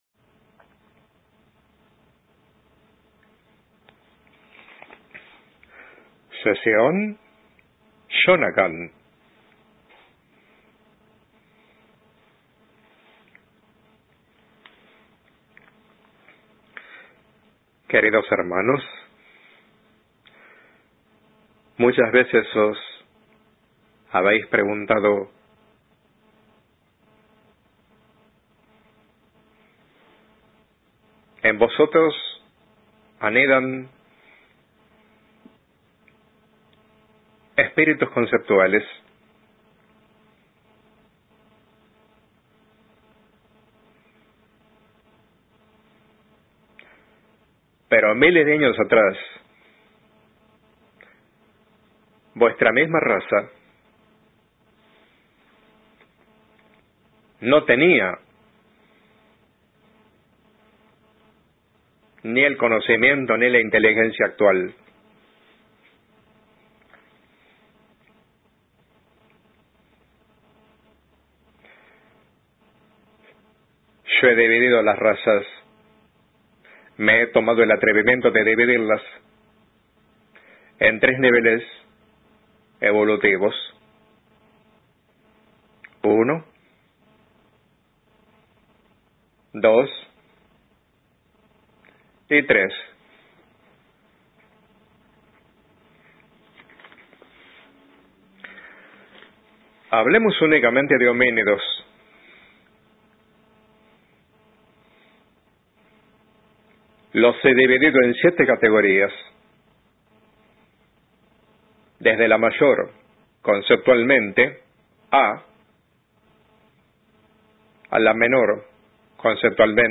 Psicointegración 19/08/2015 Profesor